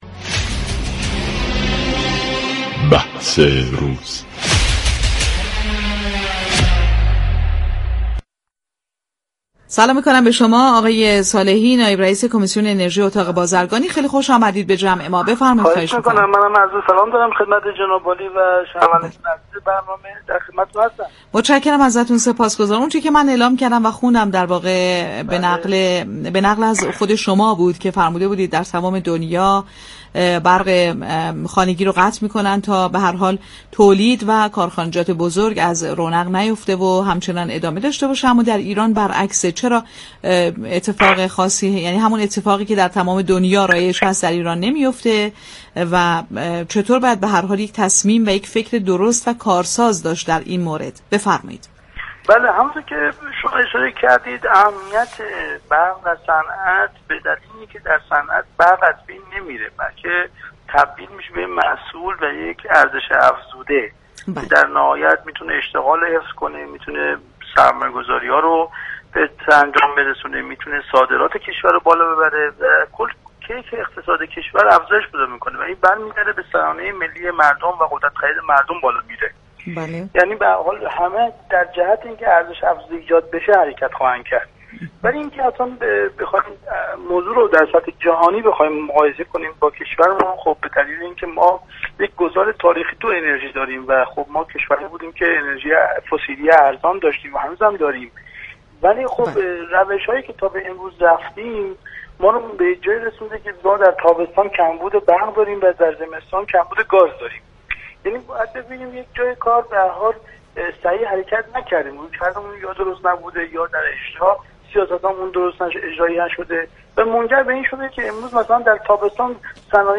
در گفت‌وگو با بازار تهران رادیو تهران